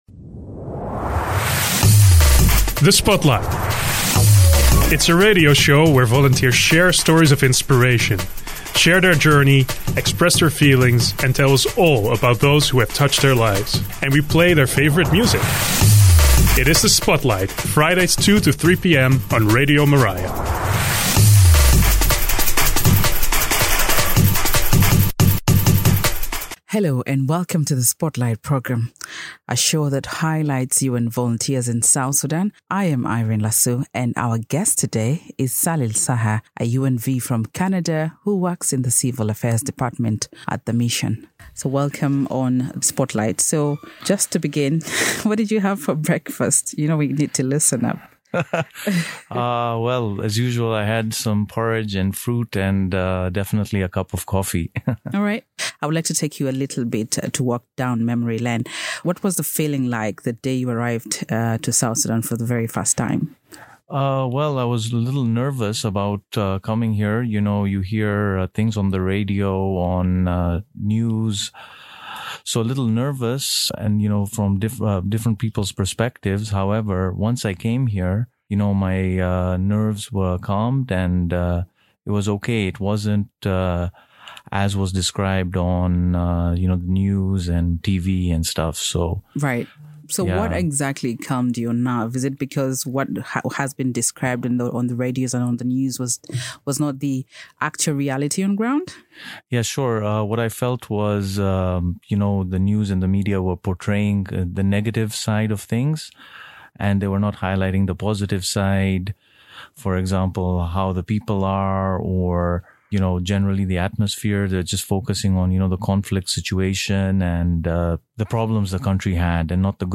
The Spotlight Program is a radio show where volunteers share their stories of inspiration from 14:00 to 15:00 every Friday on Radio Miraya.